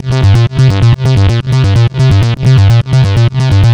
Old Order C 128.wav